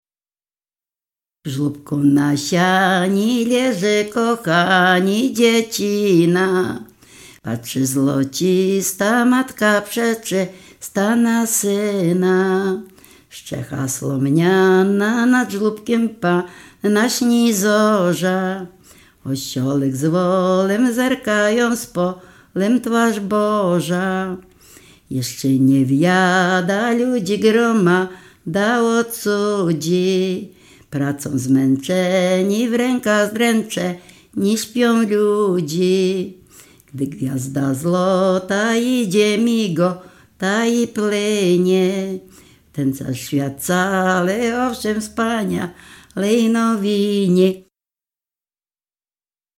W wymowie Ł wymawiane jako przedniojęzykowo-zębowe;
Kolęda